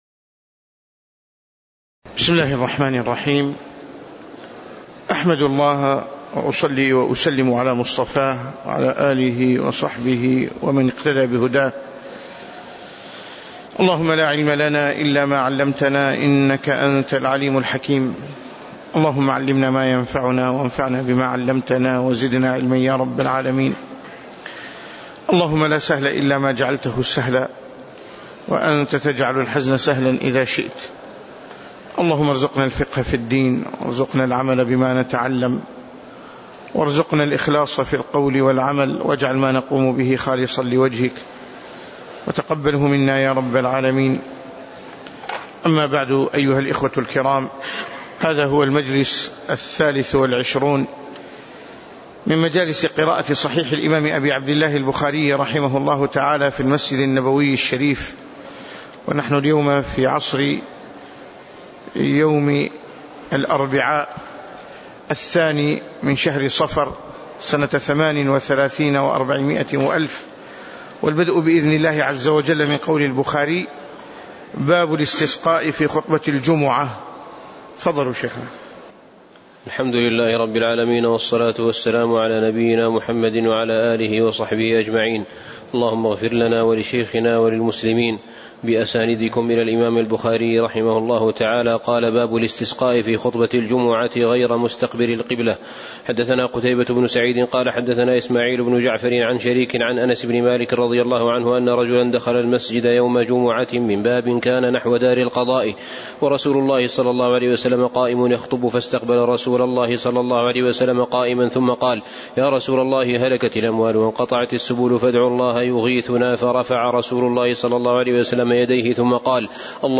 تاريخ النشر ٢ صفر ١٤٣٨ هـ المكان: المسجد النبوي الشيخ